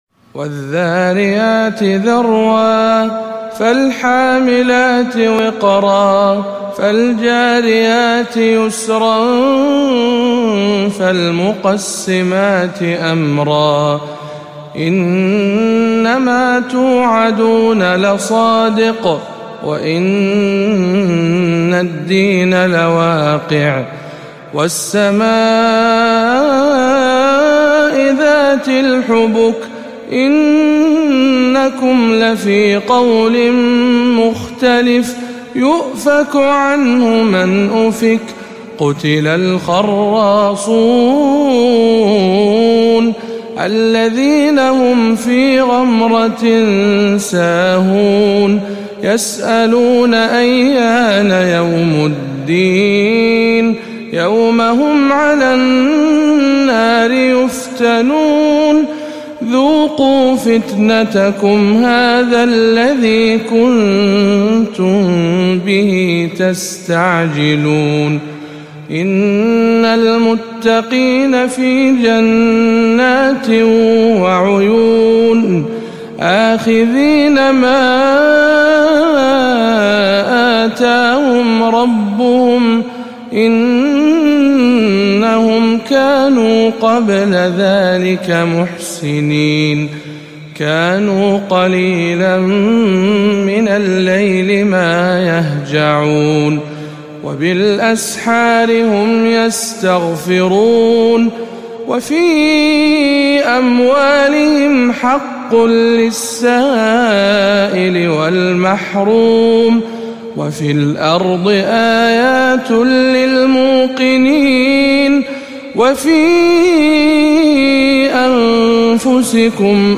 سورة الذاريات بمسجد الحسين بن علي بخليص